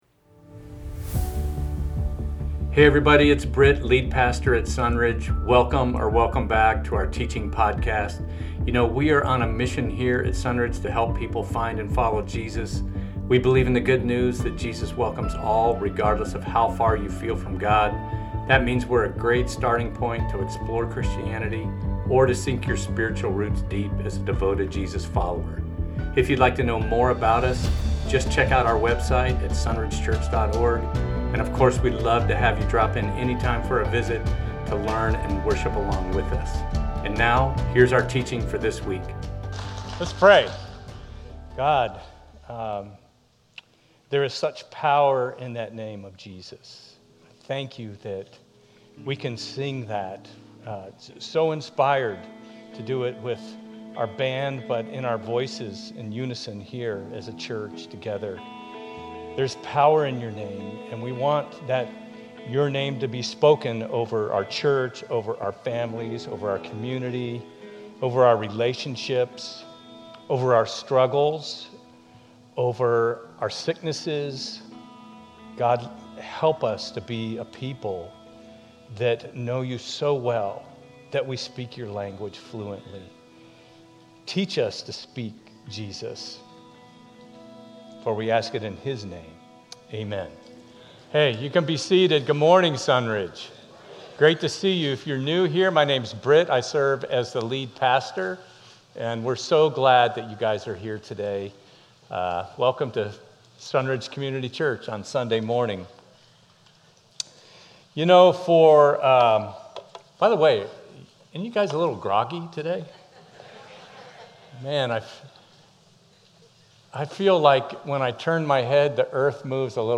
Becoming Like Jesus - Sermons at Sunridge Church in Temecula.
Sermon Audio